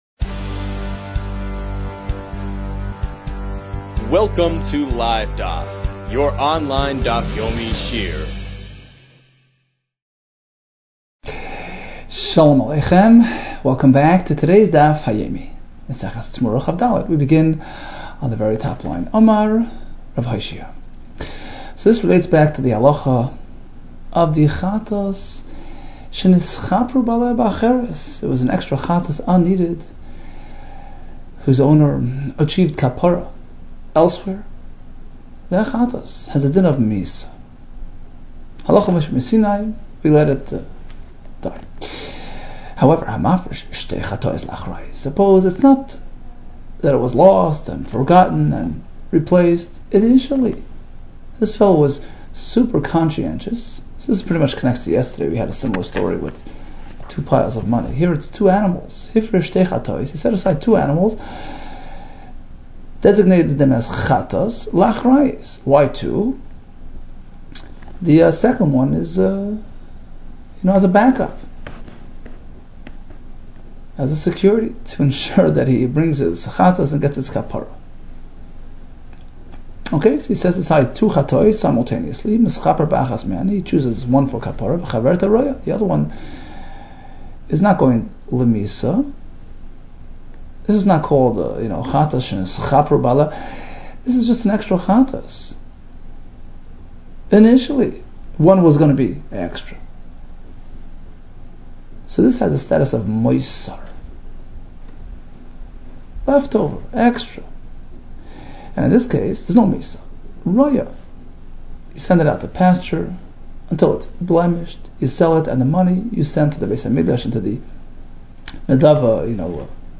Temurah 23 - תמורה כג | Daf Yomi Online Shiur | Livedaf